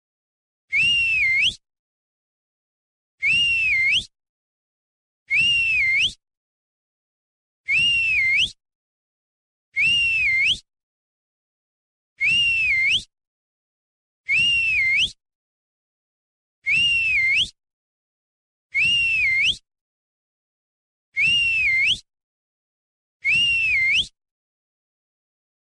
Assobio | Efeitos Sonoros Sound Effects Free Download
assobio | efeitos sonoros sound effects free download